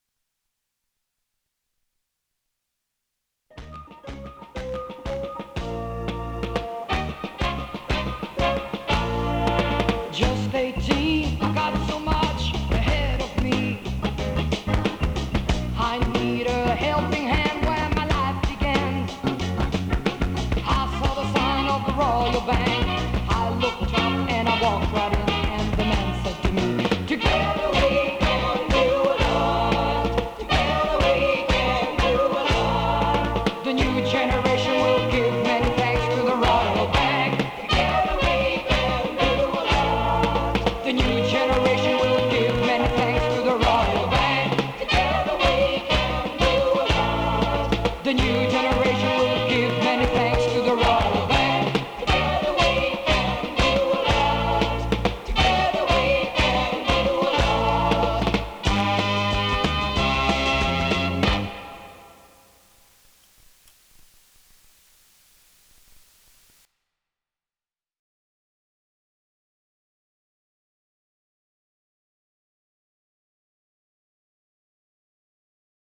Enregistré en Angleterre, Olympic Sound Studio, Barnes.